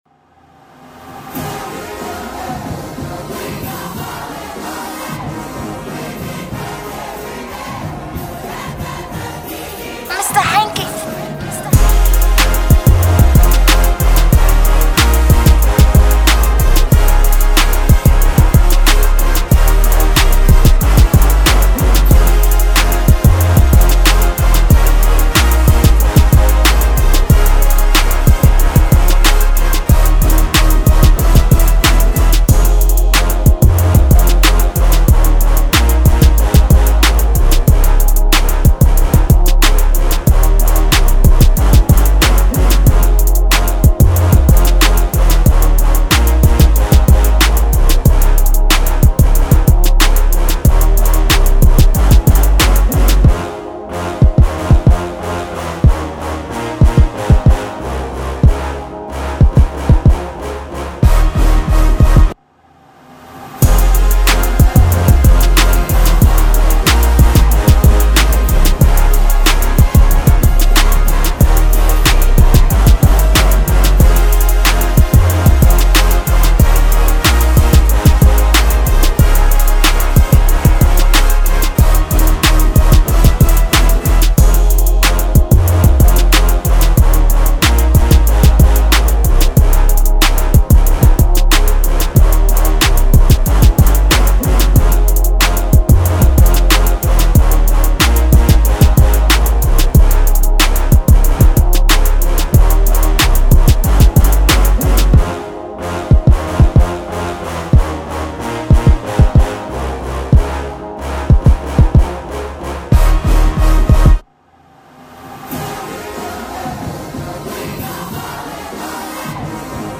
2026 in Official Instrumentals , Rap Instrumentals